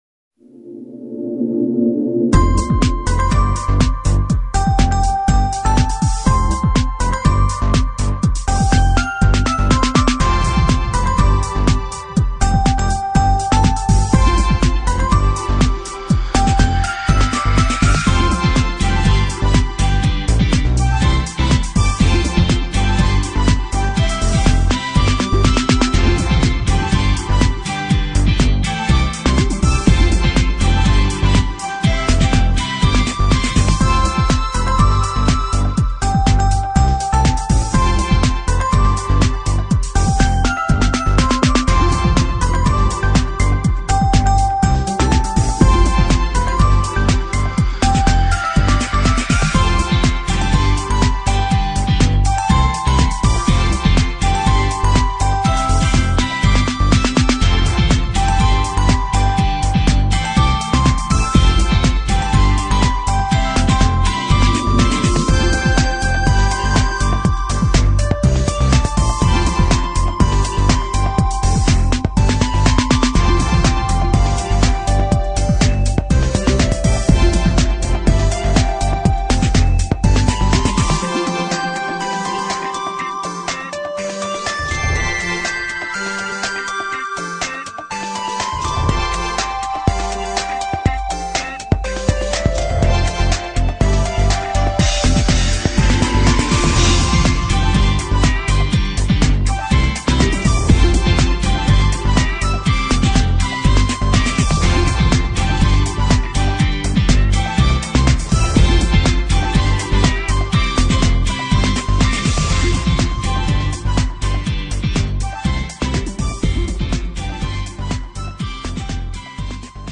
BGM: